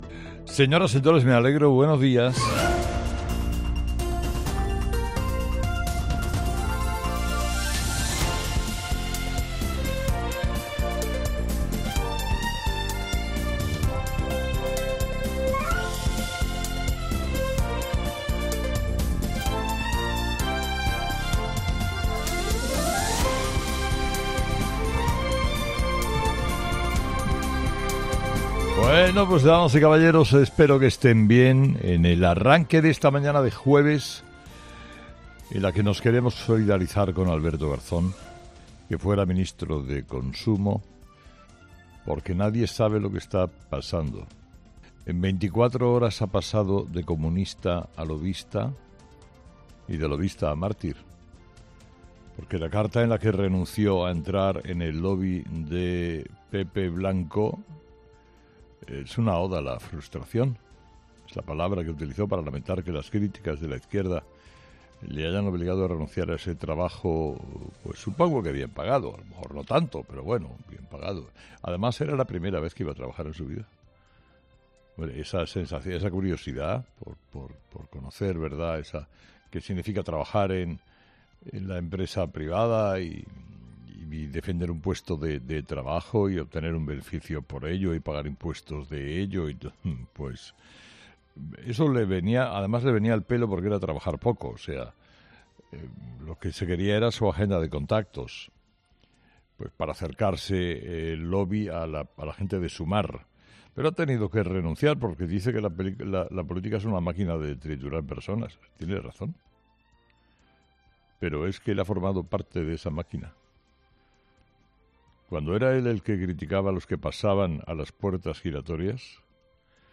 AUDIO: Escucha el análisis de Carlos Herrera a las 06:00 en Herrera en COPE del jueves 15 de febrero